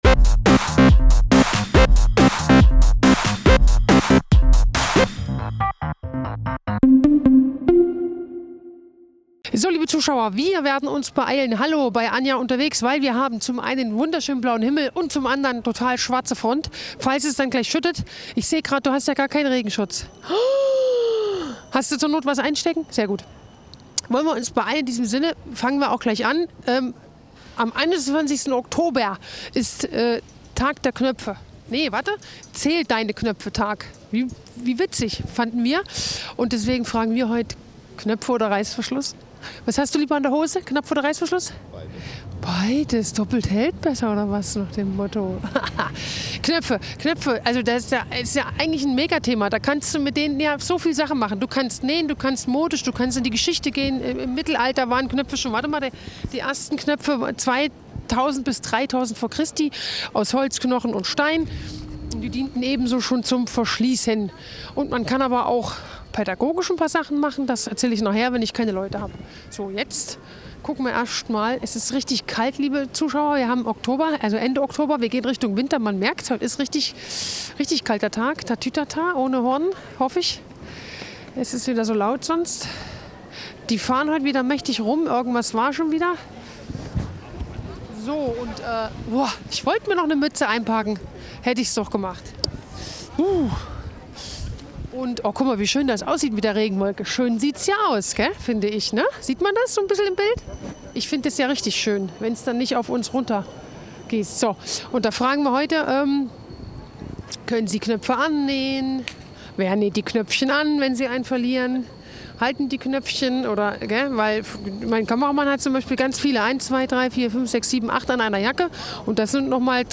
Wir zeigen dies an dieser Stelle ungeschnitten.